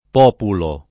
populo [ p 0 pulo ]